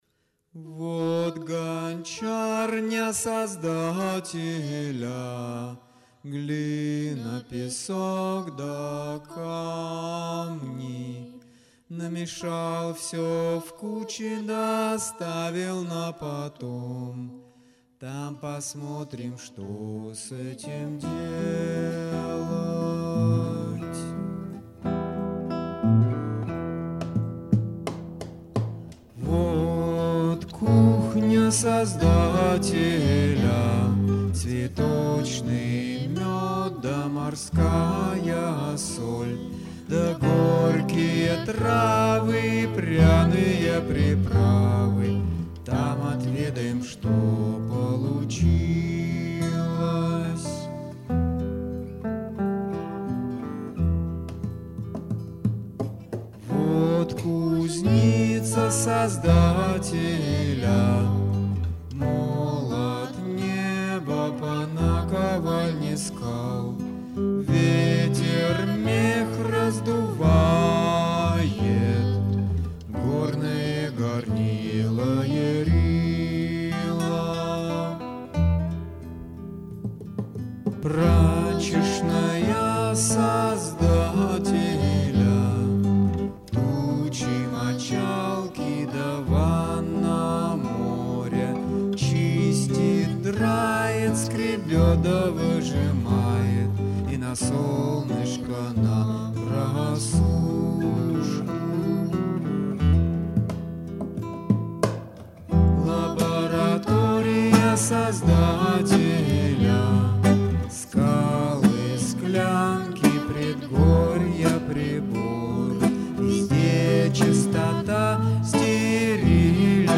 • Автор музыки: Амер народная песня в версии Джоан Баез
• Жанр: Авторская песня